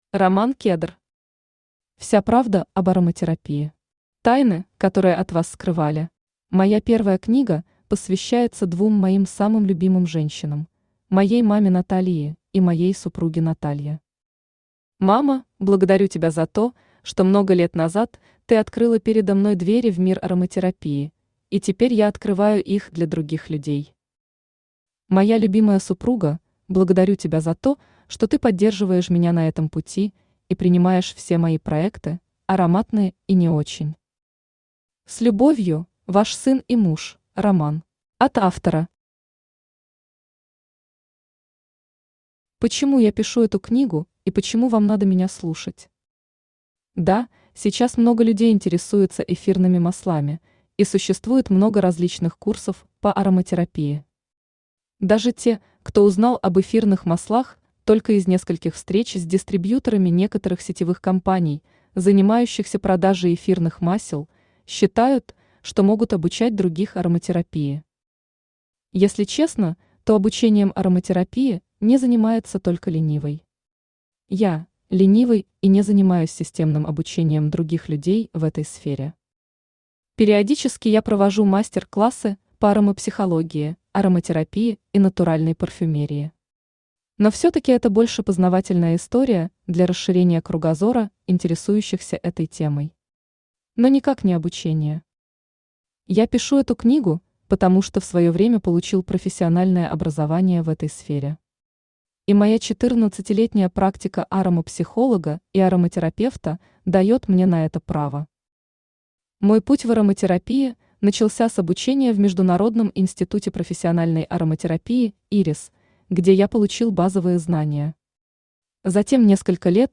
Аудиокнига Вся правда об ароматерапии.
Aудиокнига Вся правда об ароматерапии. Тайны, которые от вас скрывали Автор Роман Кедр Читает аудиокнигу Авточтец ЛитРес.